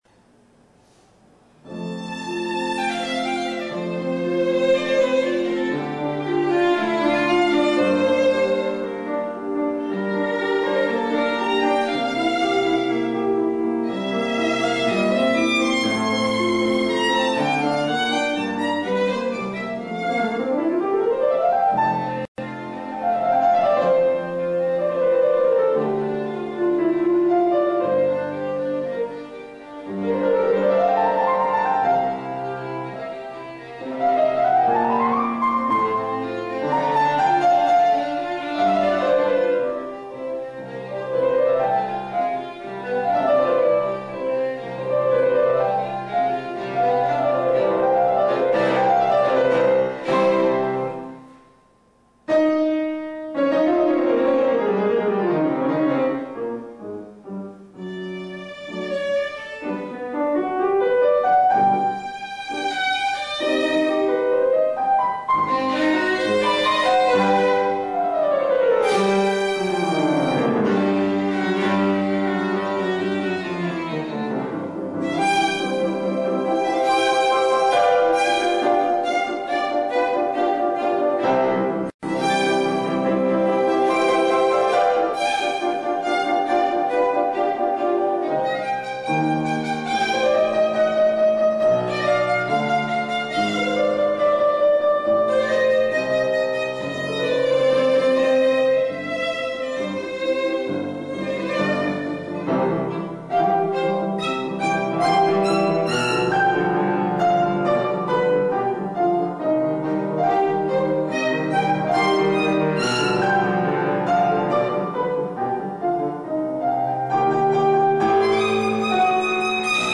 Violino